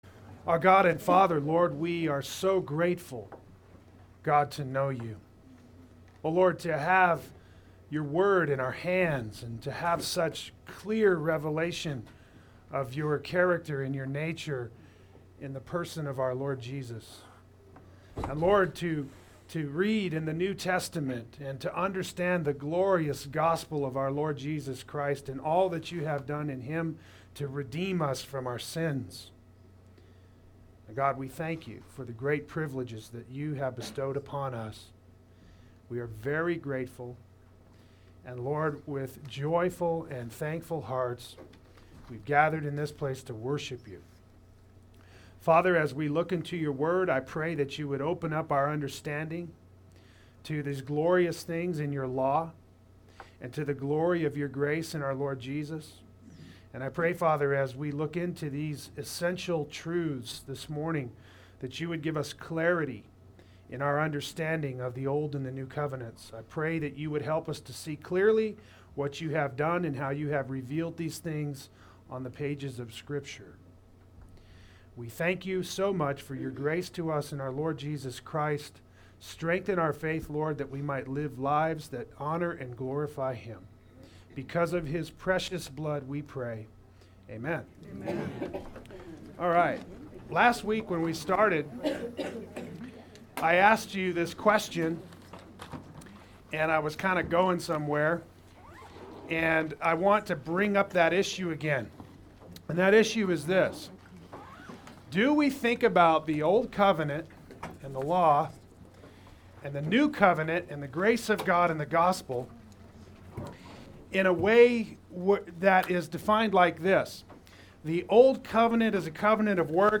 Play Sermon Get HCF Teaching Automatically.
Continuity vs. Discontinuity Adult Sunday School